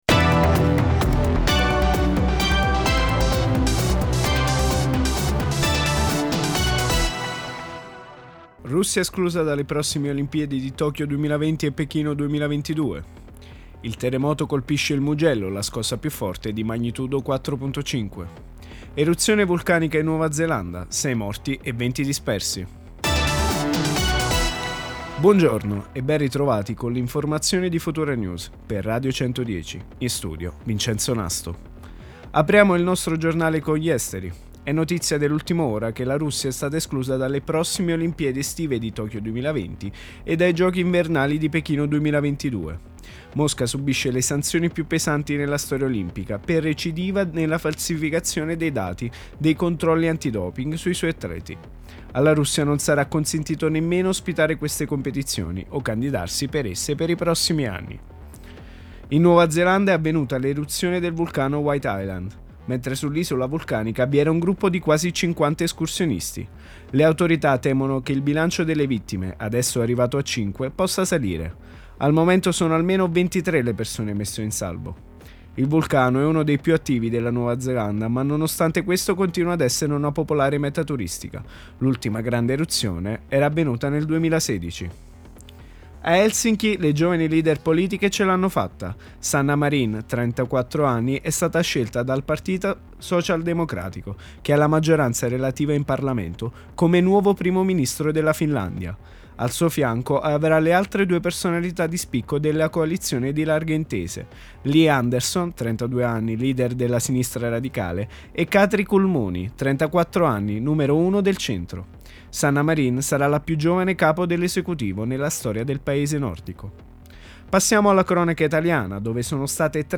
Gr Futura News per Radio 110 del 9/12/2019